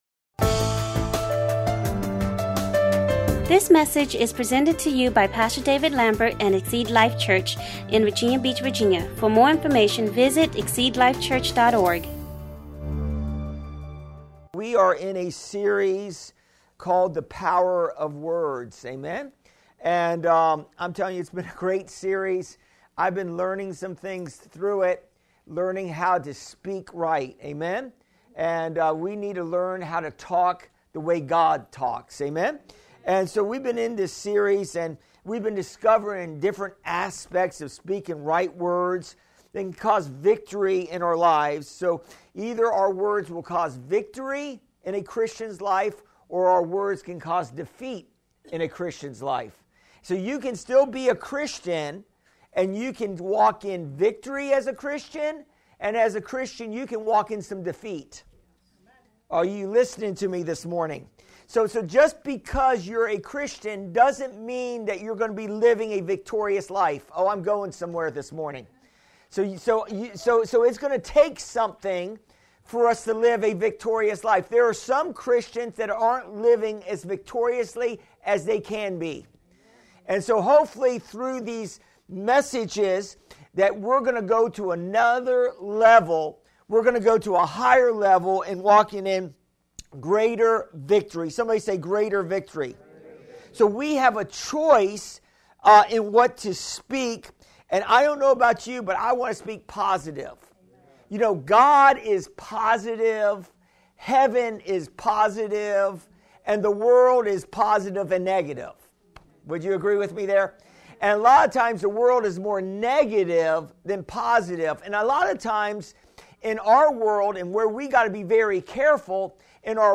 Exceed Life Church current sermon.